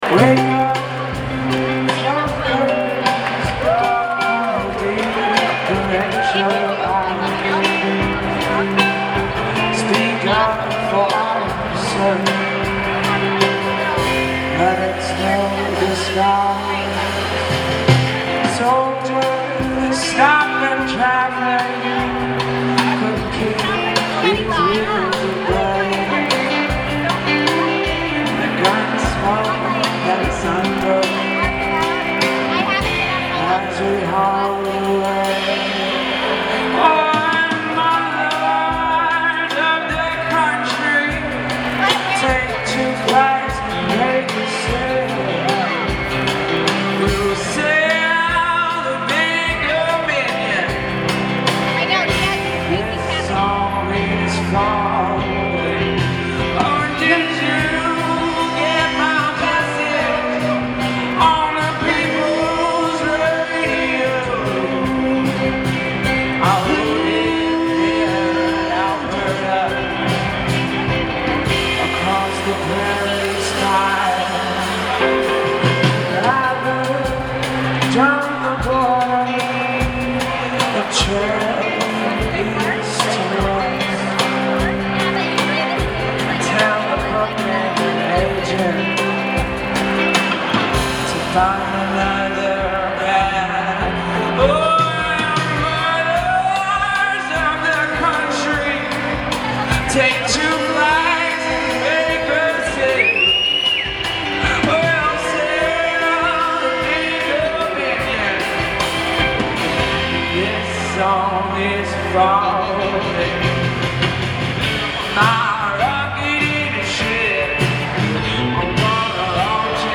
Art Gallery Of Ontario Toronto ON - September 3 2015
Source : Sony Digital MP3 recorder -> MP3